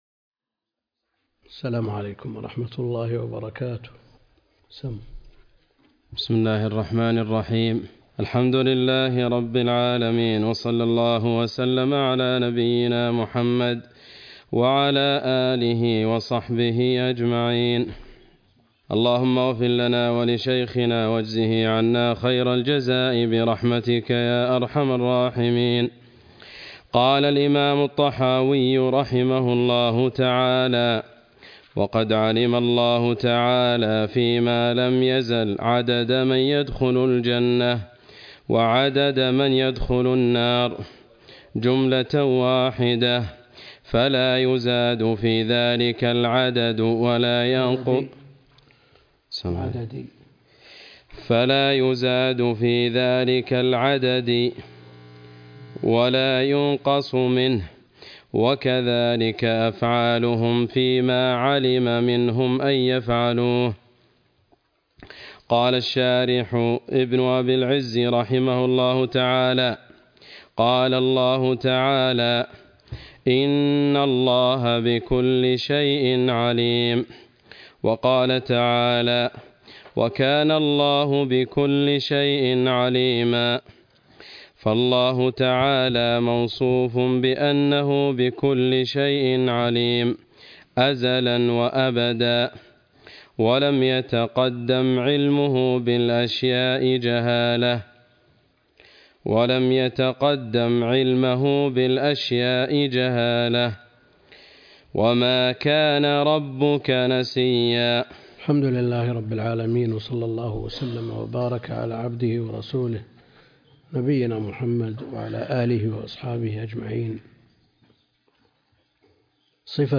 عنوان المادة الدرس (34) شرح العقيدة الطحاوية تاريخ التحميل السبت 21 يناير 2023 مـ حجم المادة 17.53 ميجا بايت عدد الزيارات 256 زيارة عدد مرات الحفظ 126 مرة إستماع المادة حفظ المادة اضف تعليقك أرسل لصديق